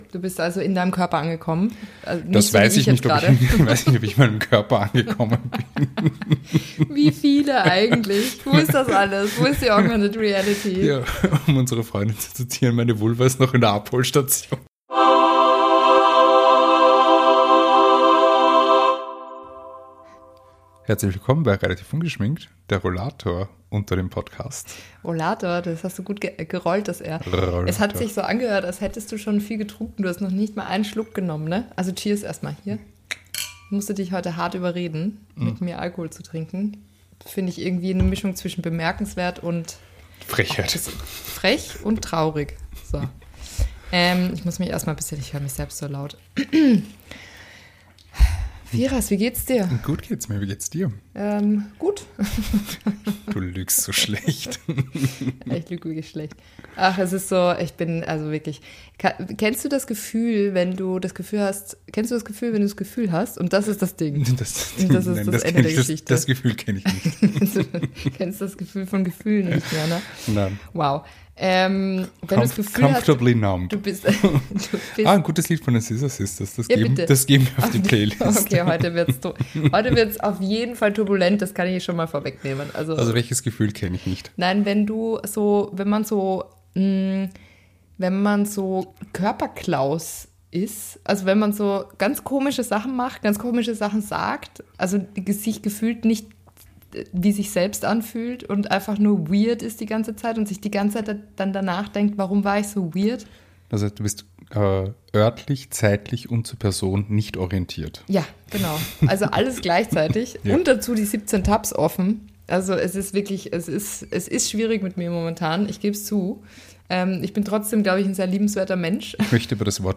Intro: Sound Effect
Outro: Sound Effect